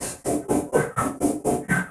RI_RhythNoise_125-04.wav